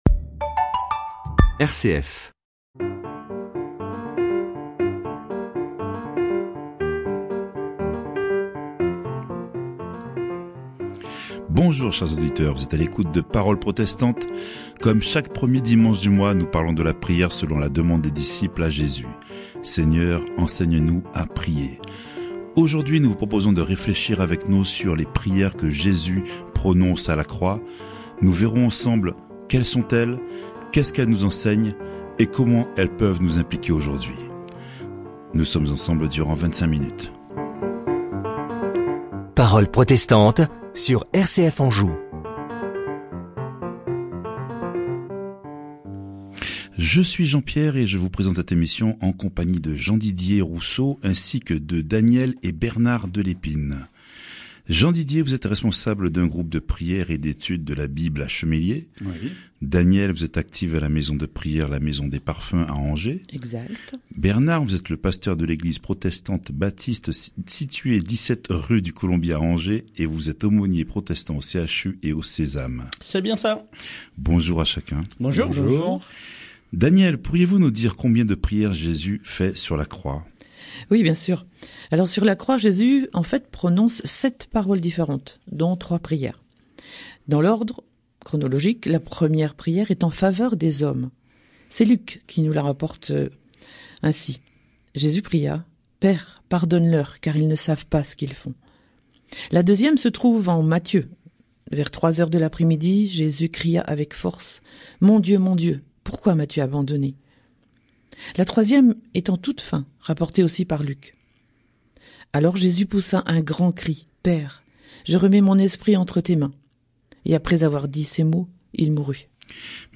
Séries : Emission de radio RCF | Découvrons les prières de Jésus alors qu’il est en croix, au nombre de trois.